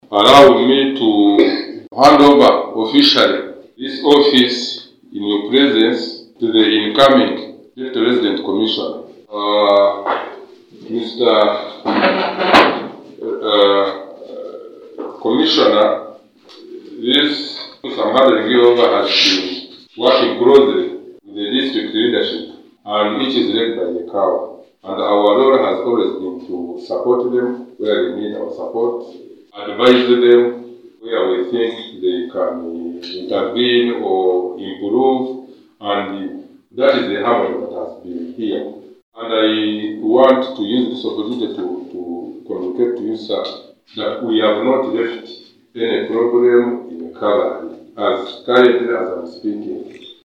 Kabale-In a significant leadership transition, Michael Muramira Kyakashari has officially assumed office as the new Deputy Resident District Commissioner (RDC) of Kabale District, replacing Christopher Aine in a ceremony held at the Deputy RDC’s office on Makanga Hill, Kabale Municipality.